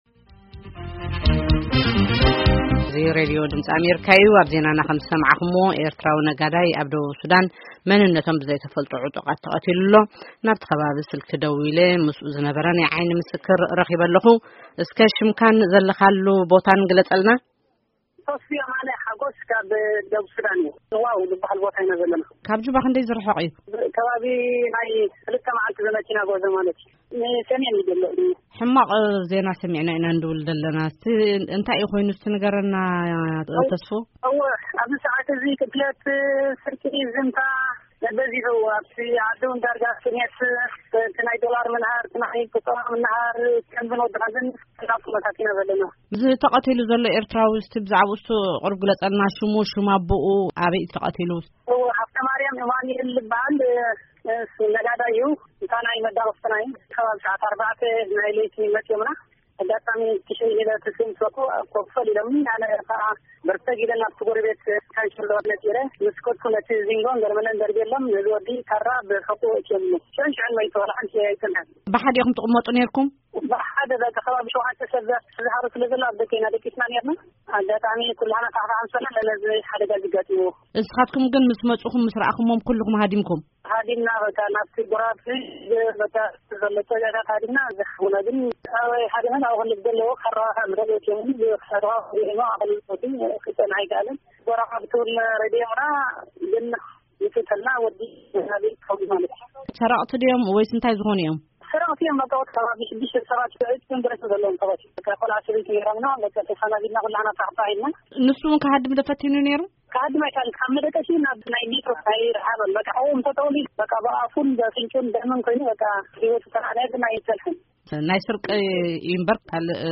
ቃለ-መጠይቕ ምስ ናይ ዓይኒ ምስክር ኣብ መቕተልቲ ሓደ ኤርትራዊ ኣብ ደቡብ ሱዳን